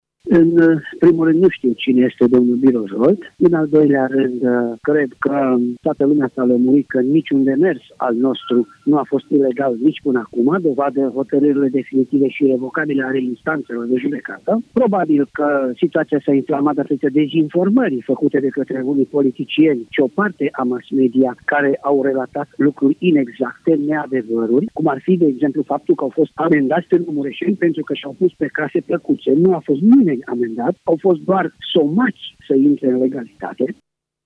În replică, şeful Poliţiei Locale, Valentin Bretfelean, a spus că nu ştie cine este Biro Zsolt: